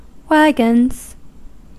Ääntäminen
Ääntäminen US Haettu sana löytyi näillä lähdekielillä: englanti Käännöksiä ei löytynyt valitulle kohdekielelle. Wagons on sanan wagon monikko.